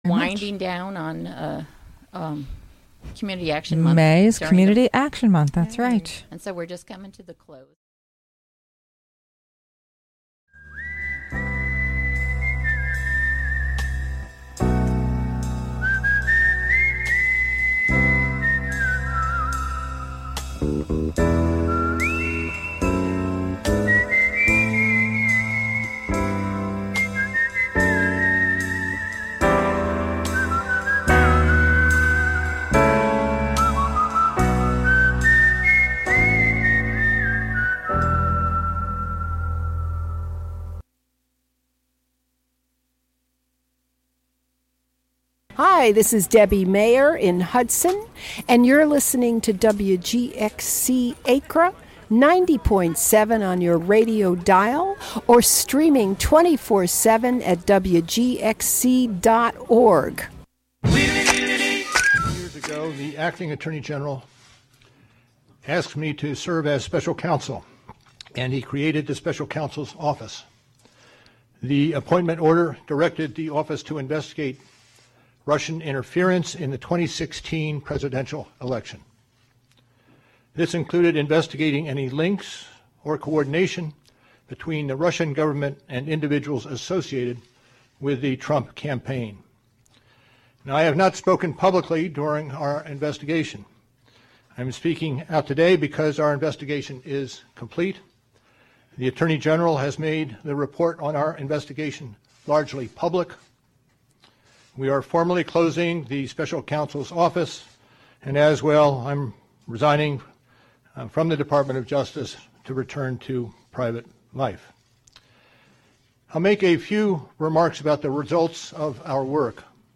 featuring protest songs from the past and present, performed by a lineup of contemporary musicians and movement leaders. Recorded live on May 17 at the Interference Archive in Brooklyn, NY.